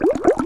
Sfx Boat Through Water Sound Effect
sfx-boat-through-water-1.mp3